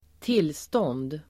Uttal: [²t'il:stån:d]